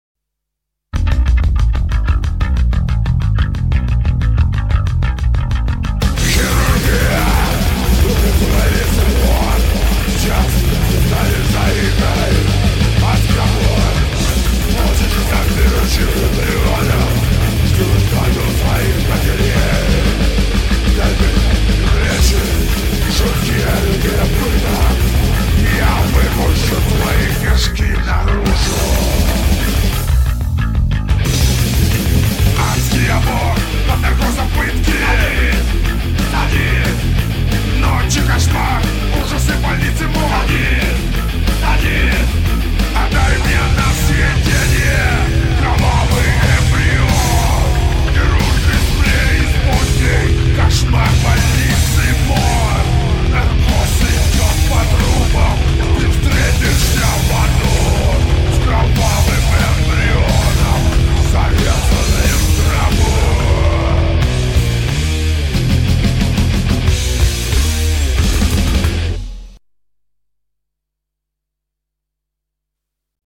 Назад в Metal
Брутальщина